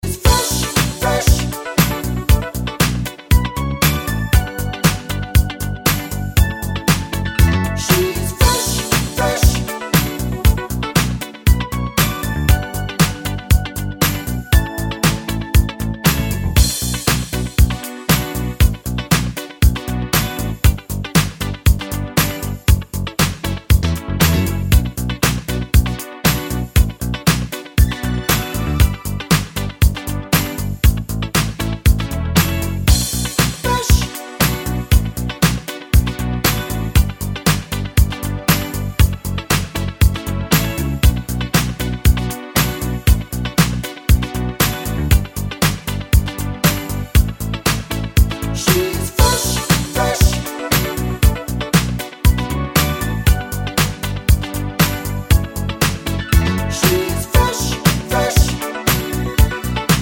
no Backing Vocals Disco 3:46 Buy £1.50